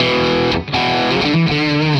Index of /musicradar/80s-heat-samples/120bpm
AM_HeroGuitar_120-E02.wav